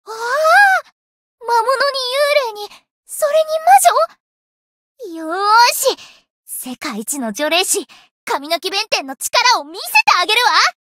灵魂潮汐-神纳木弁天-人偶初识语音.ogg